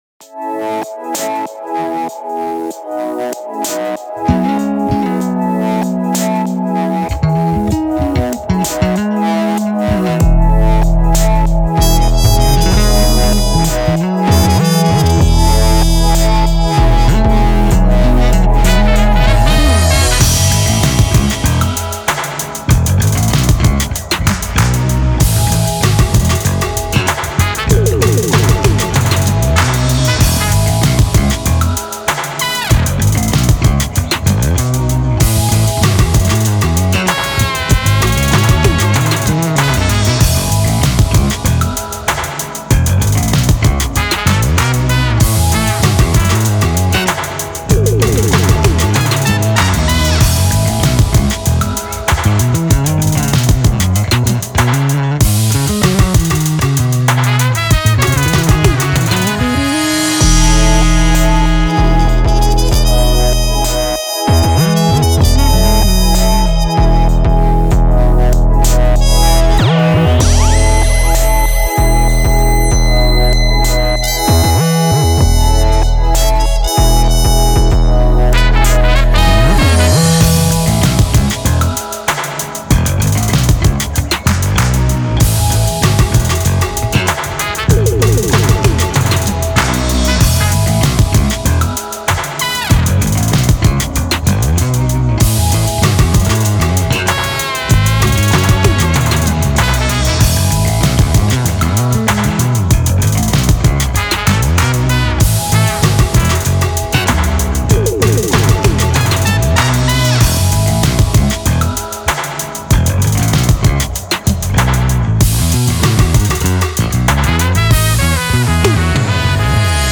2016 Genres: Indie, Indie Rock, Rock Available Now On